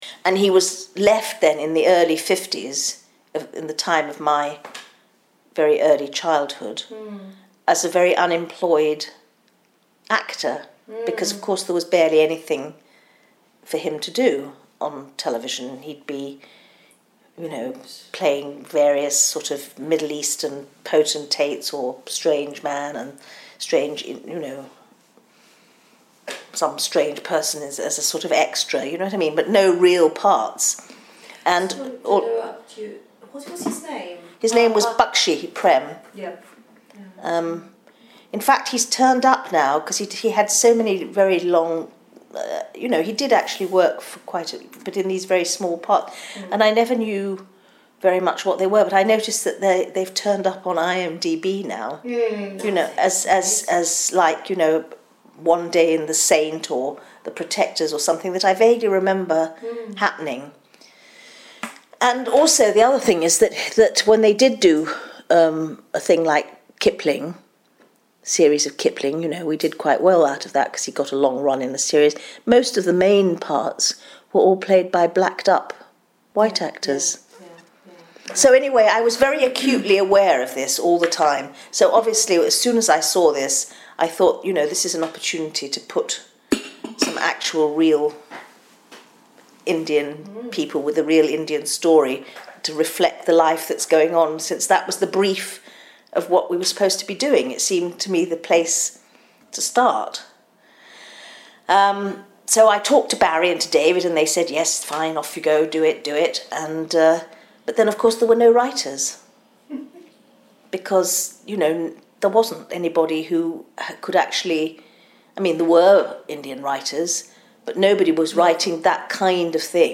including extracts from an interview